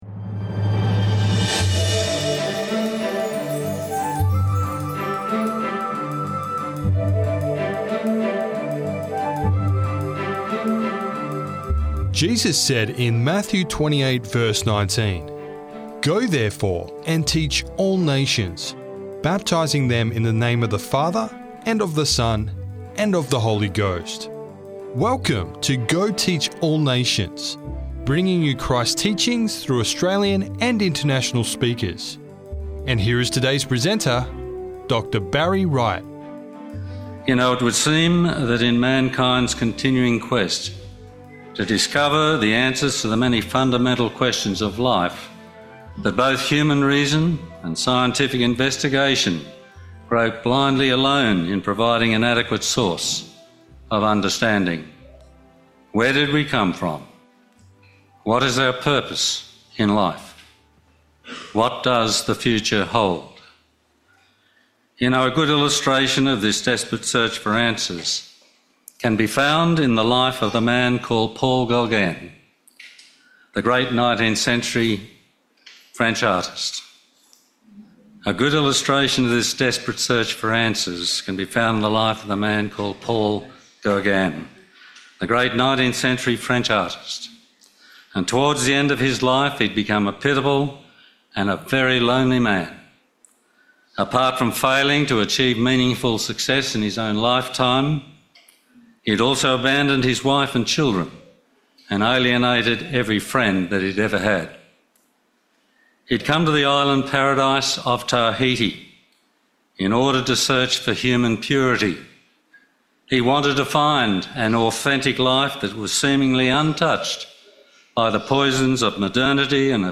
Prophecy, False Prophets & God's True Gift – Sermon Audio 2615